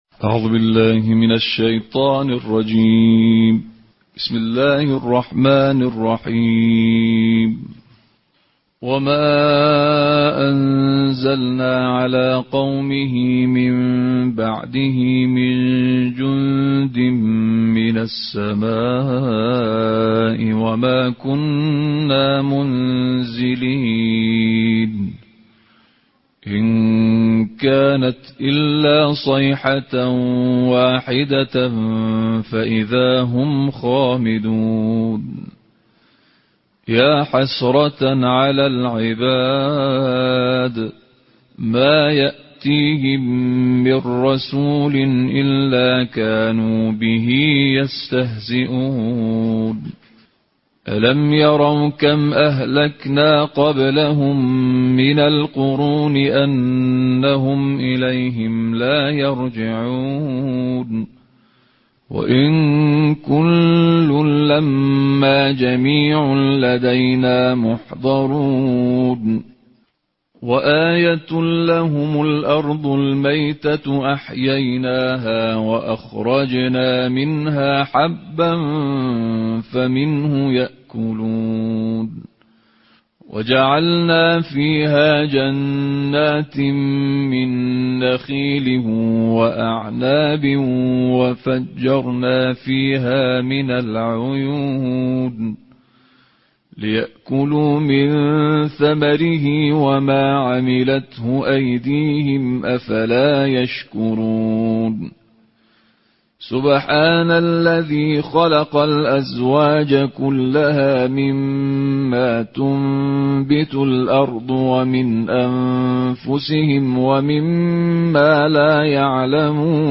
Ежедневное чтение Корана: Тартиль 23-го джуза